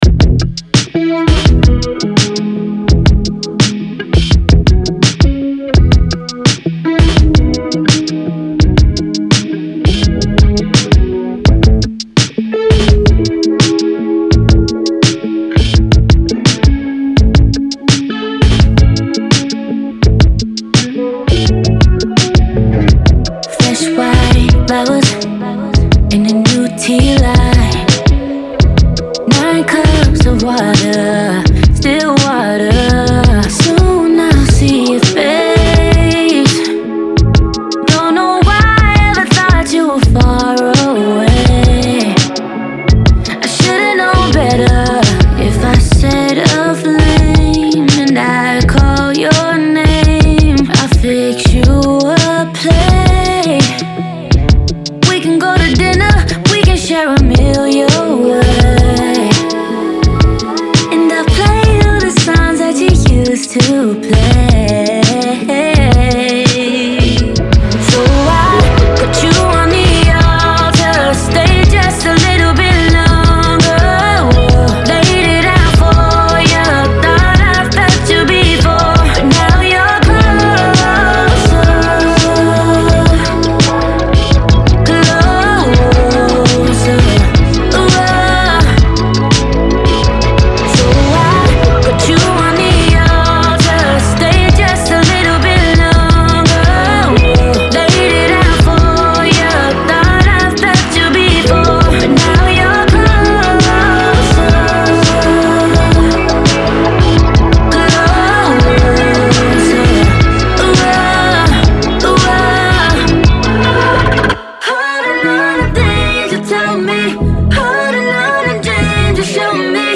Genre : Funk, R&B, Soul